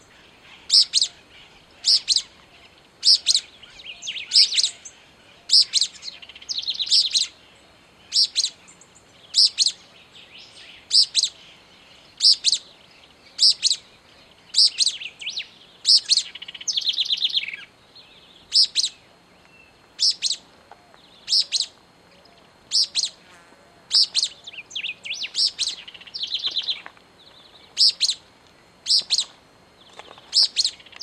Uí-pi (Synallaxis albescens)
Nome em Inglês: Pale-breasted Spinetail
Localidade ou área protegida: Trancas
Condição: Selvagem
Certeza: Fotografado, Gravado Vocal